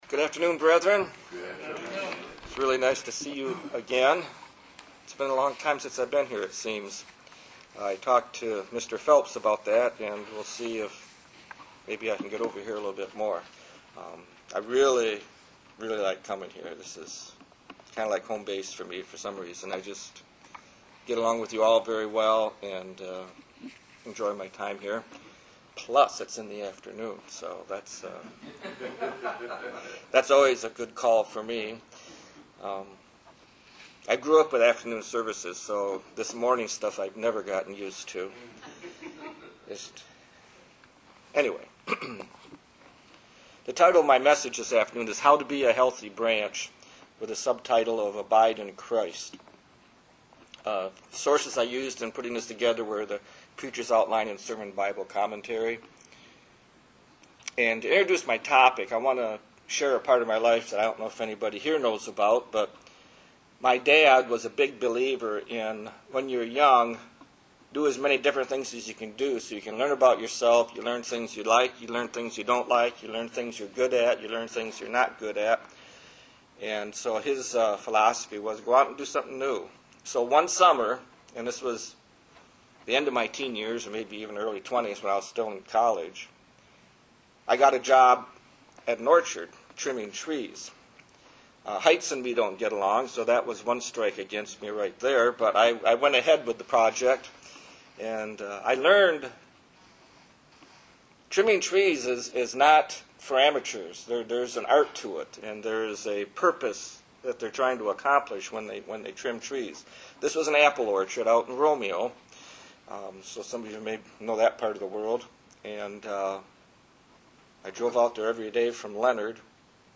Given in Detroit, MI